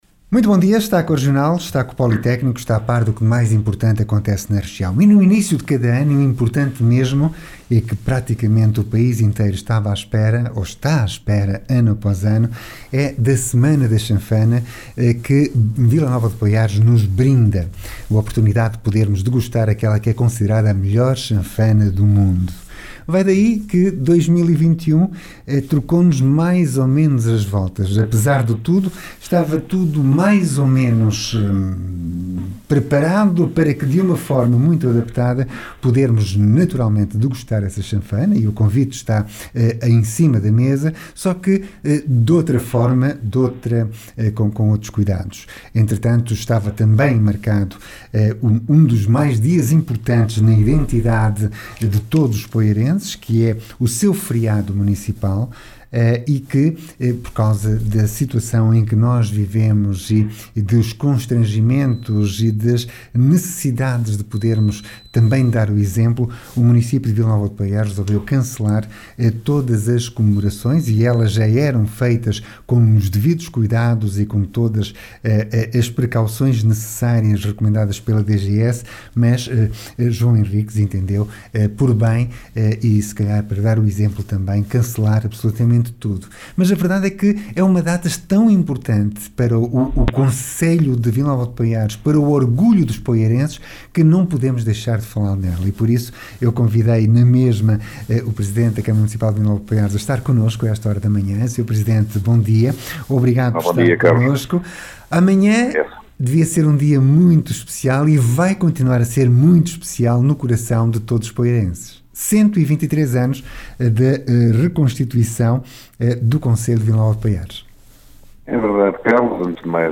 O Executivo decidiu cancelar as cerimónias que serviriam para reconhecer e agradecer a entidades, instituições e pessoas como conta João Miguel Henriques, Presidente da Câmara Municipal.